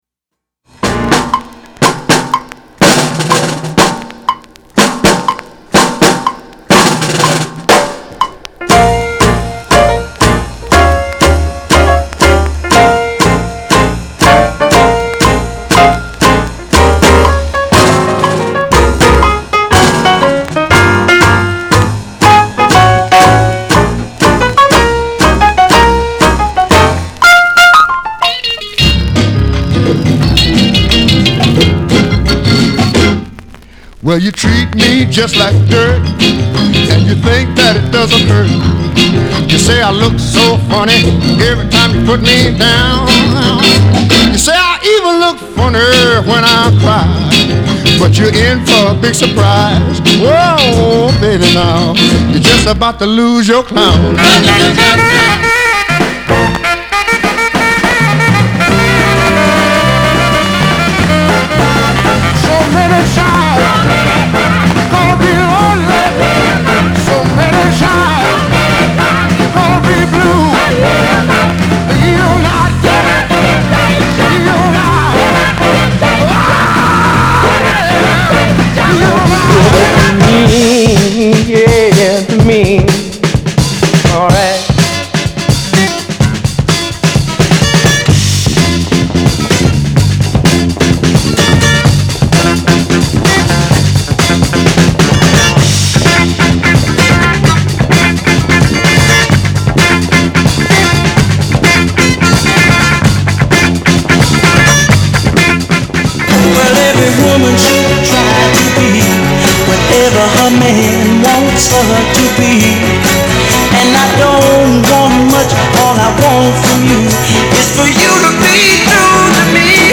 エキゾチック・ファンク・ブギー